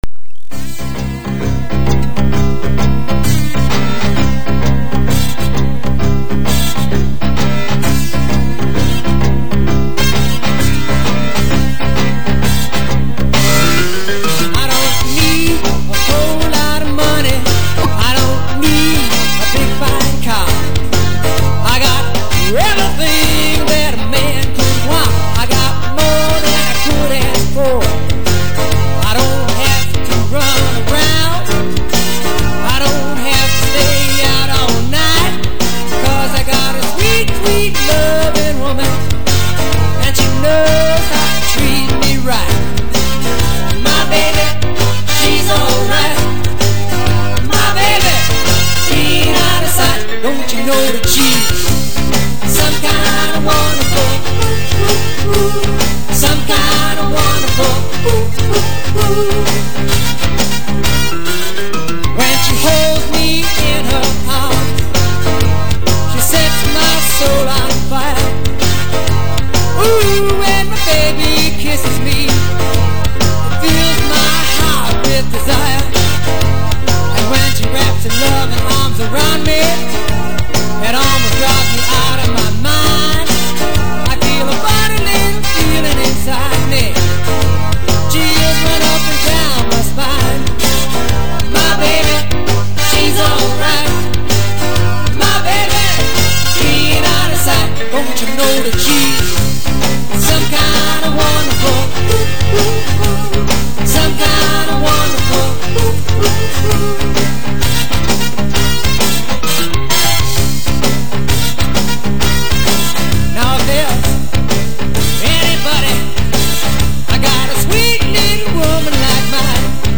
lead vocal and lead guitar.
background vocal and keyboard.